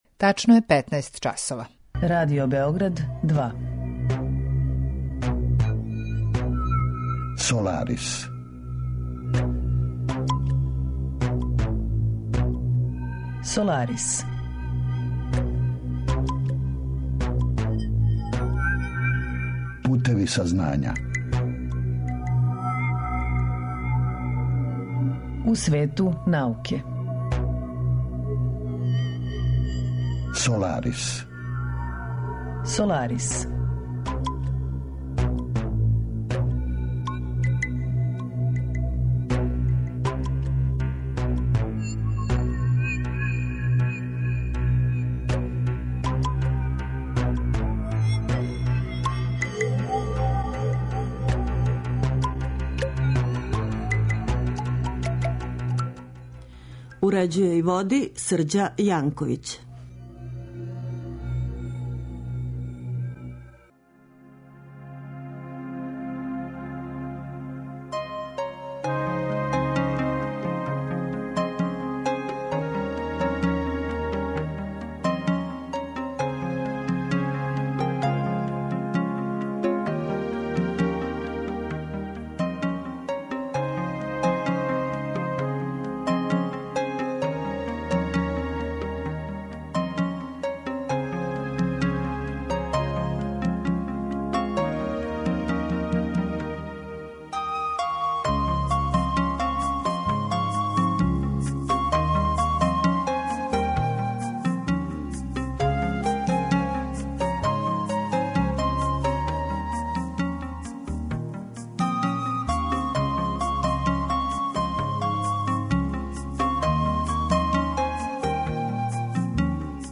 Разговор је први пут емитован 25. маја 2016. године.